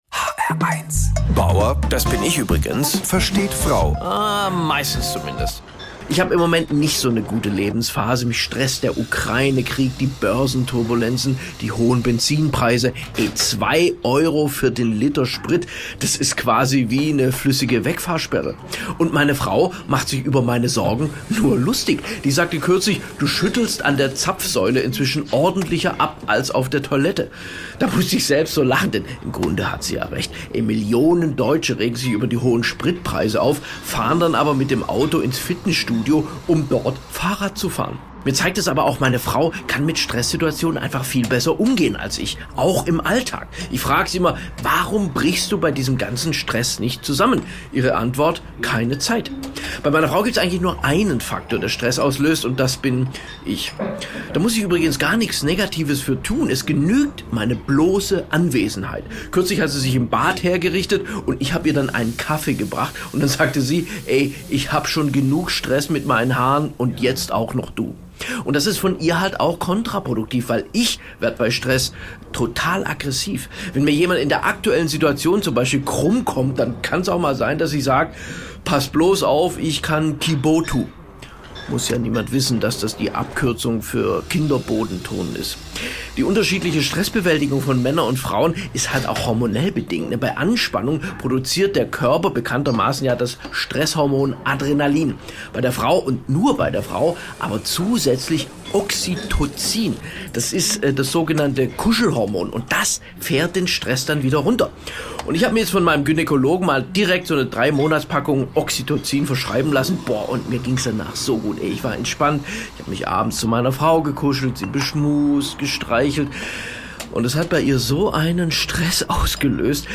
Männliche Selbstoptimierung | Comedy - 19.10.2022